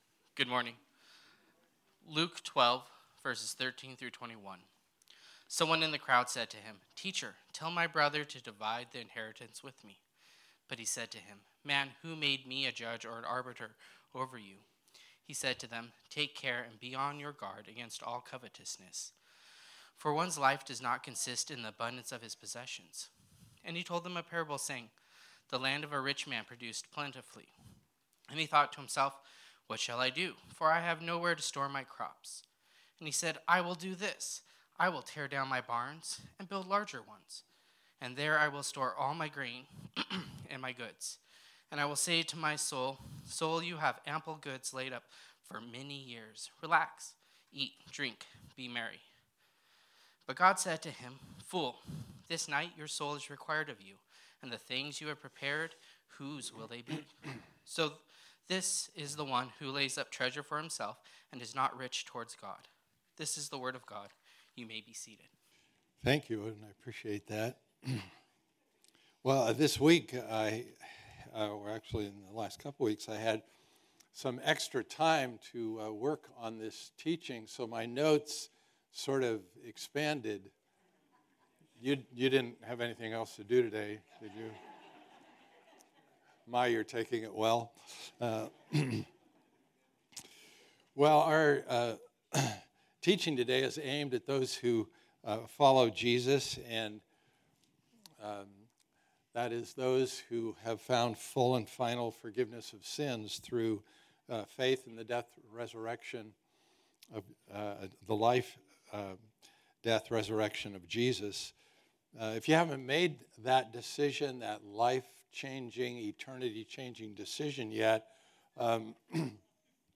Type: Sermon